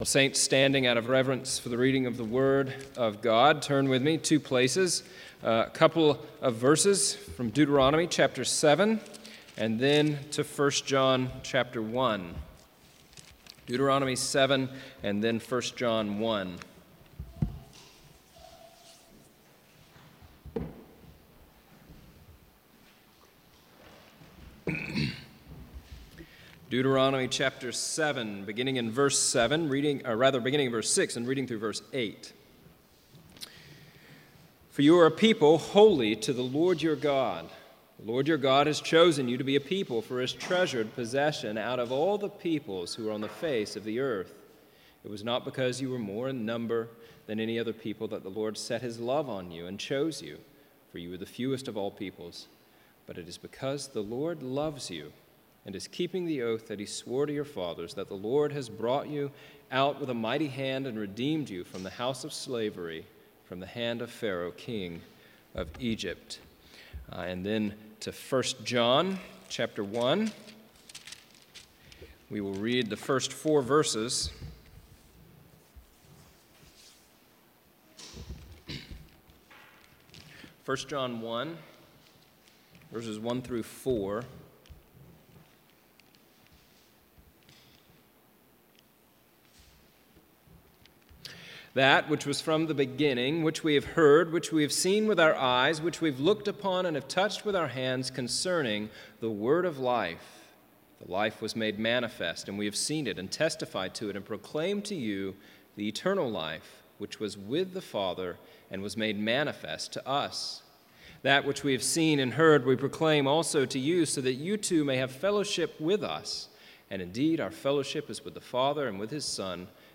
Bible Text: 1 John 1:1-4 | Preacher: Pastor